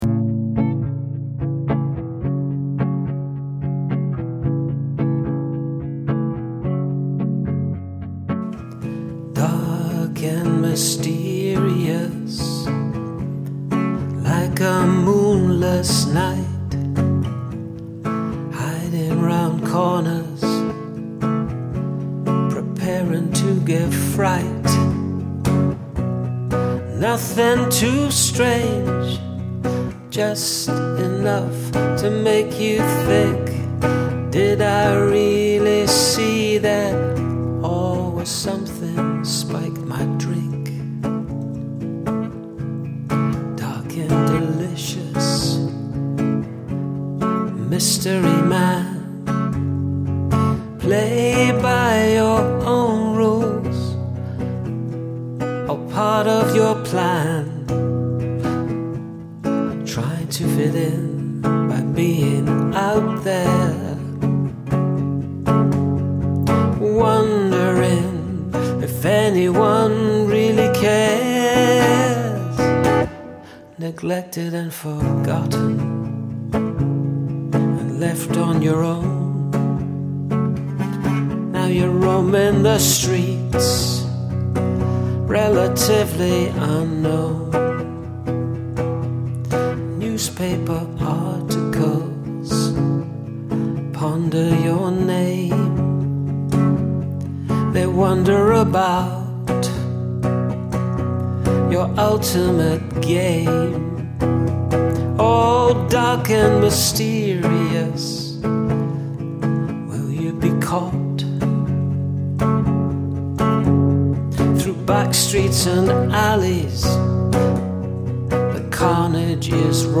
Jaunty feel but rather dark lyrics?
I personally would have gone with less jaunty music, but this could work ironically.